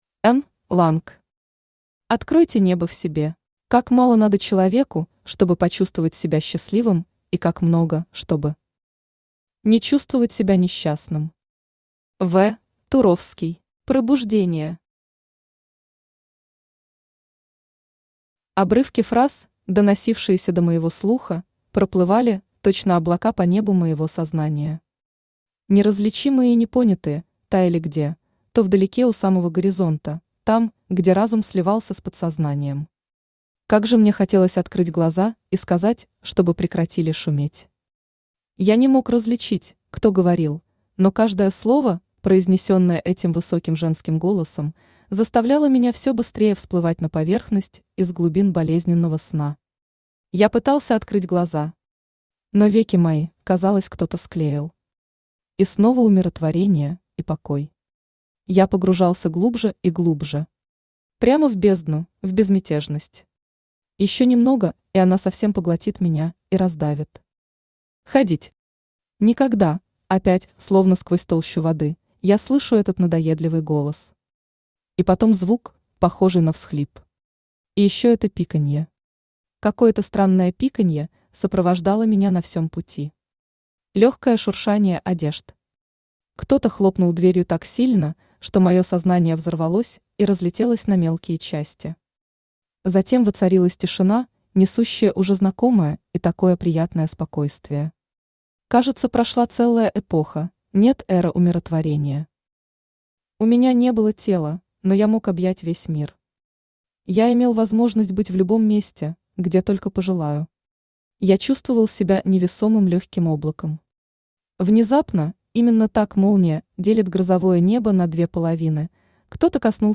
Аудиокнига Откройте небо в себе | Библиотека аудиокниг
Aудиокнига Откройте небо в себе Автор Н. Ланг Читает аудиокнигу Авточтец ЛитРес.